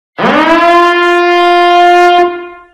Catégorie Alarme/Reveil